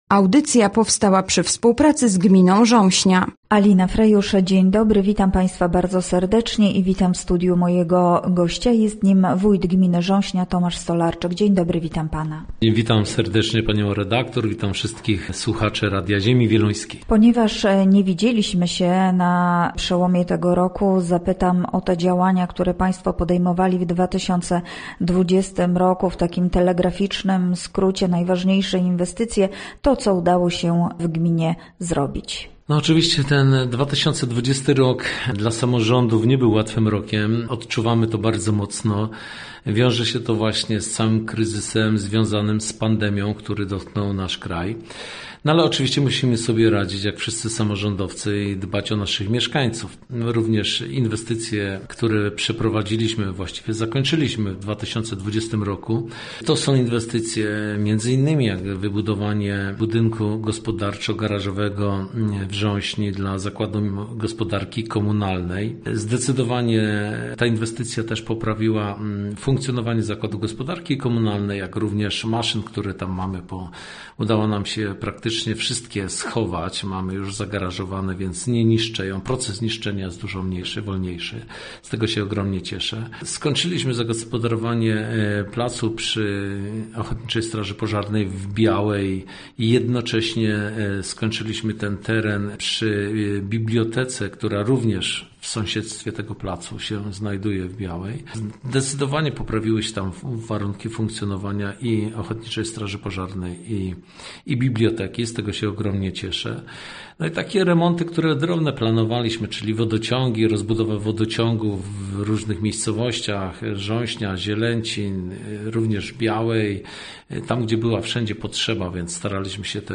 Gościem Radia był Tomasz Stolarczyk, wójt gminy Rząśnia